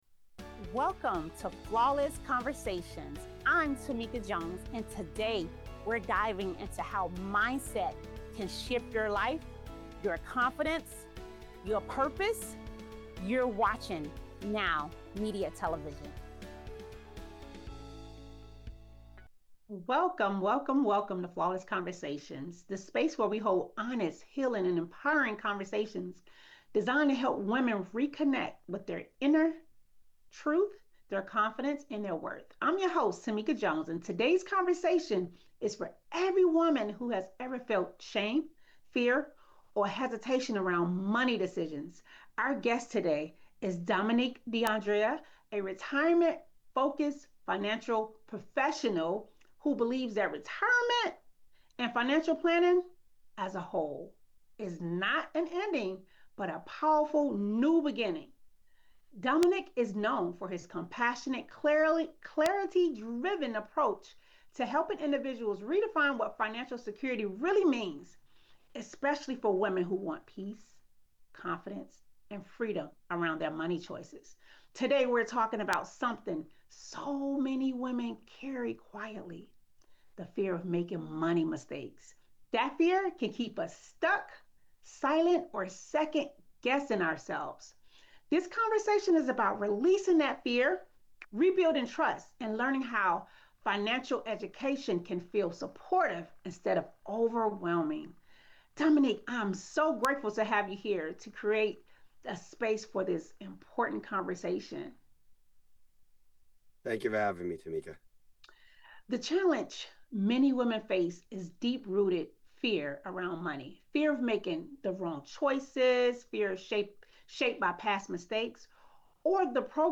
discussion on money mindset, financial confidence, retirement planning, wealth protection, and legacy building. This episode explores why so many women feel fear and shame around money decisions, and how financial education can become supportive, practical, and life-changing.